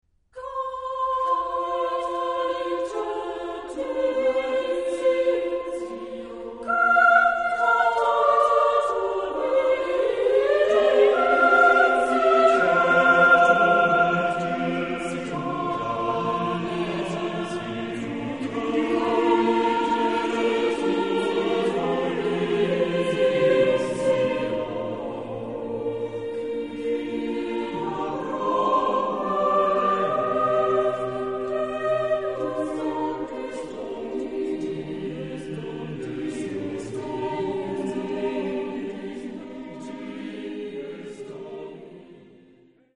Zeitepoche: 16. Jh.
Genre-Stil-Form: geistlich ; Psalm
Chorgattung: SATB  (4 gemischter Chor Stimmen )
von Jugendchor Osnabrück gesungen